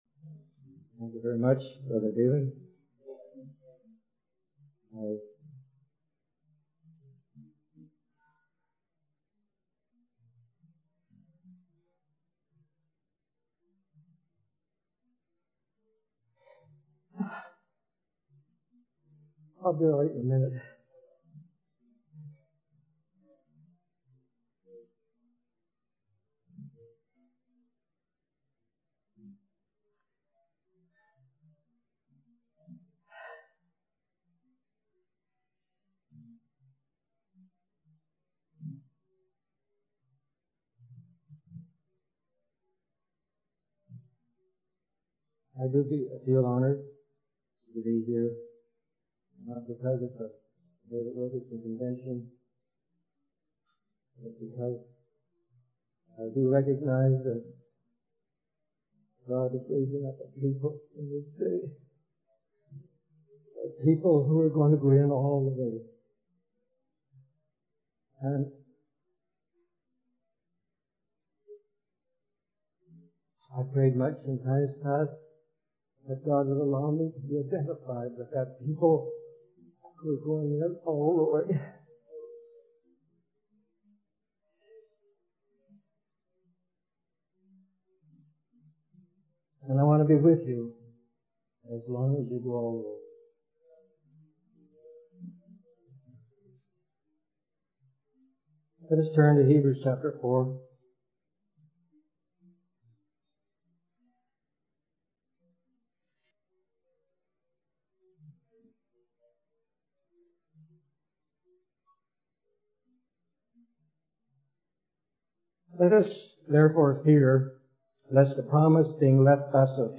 In this sermon, the preacher discusses Jesus' statement to his disciples that it is better for him to go away. He emphasizes that Jesus' departure was part of God's plan to bring about the fullness of his glory on earth.